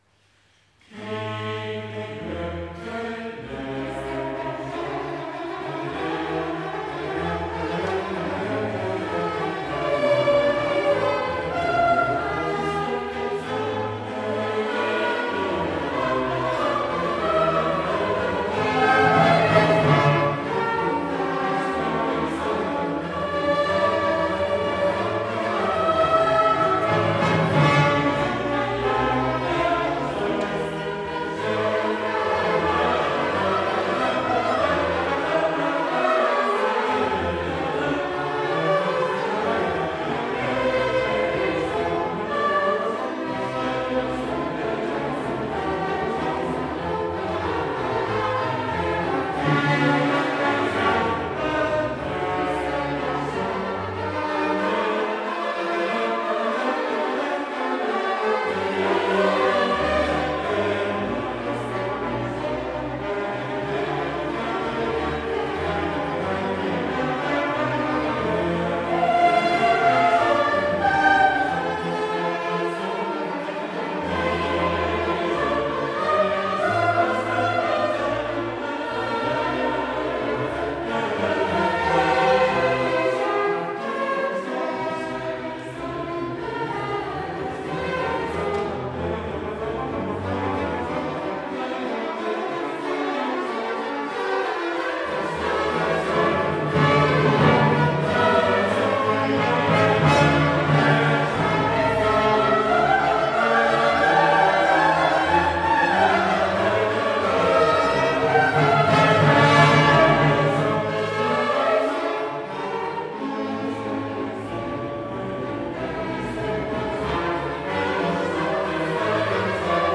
Sopran
Tenor
Bass
Orgel Capriccio Barockorchester